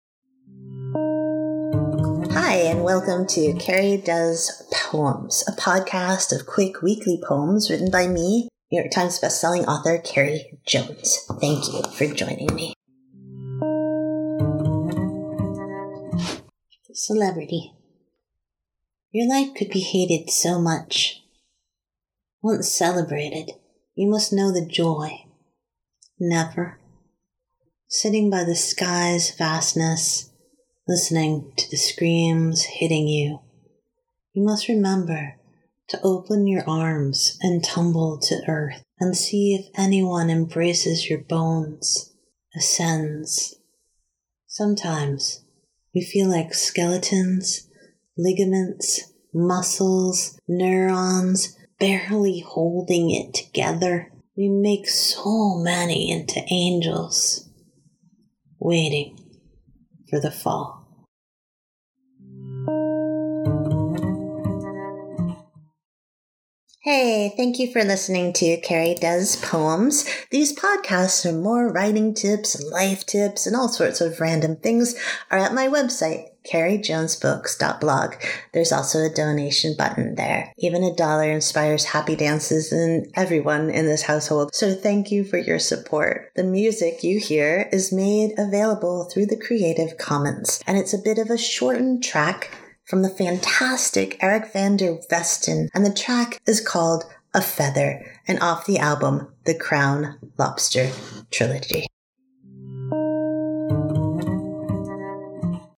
The music you hear is made available through the creative commons and it’s a bit of a shortened track from the fantastic Eric Van der Westen and the track is called "A Feather" and off the album The Crown Lobster Trilogy.